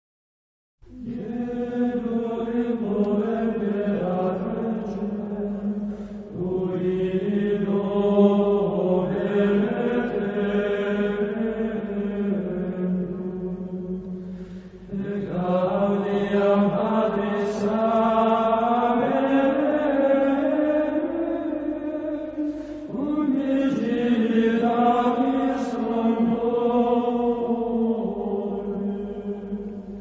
Antifona y Salmo 99 (Modo 2.)
• Coro de Monjes del Monasterio Benedictino de Santo Domingo de Silos [interprete]
• registrazione sonora di musica